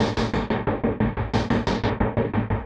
Index of /musicradar/rhythmic-inspiration-samples/90bpm